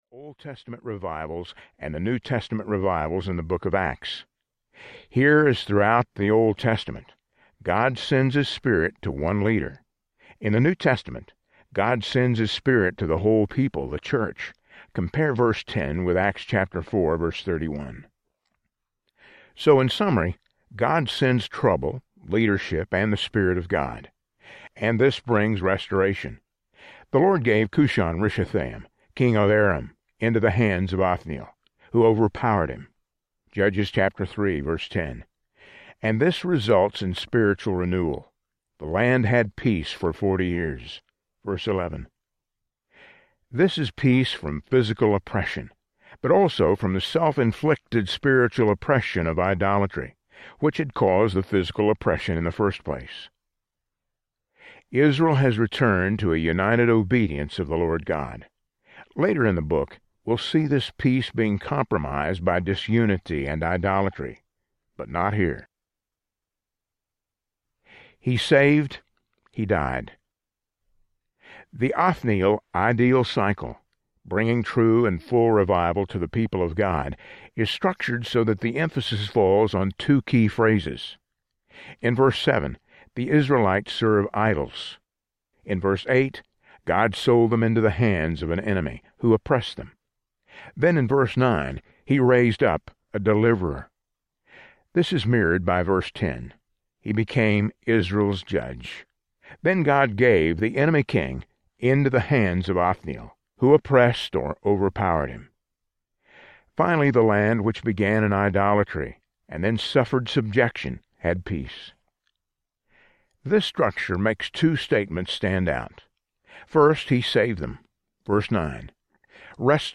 Judges For You Audiobook
Narrator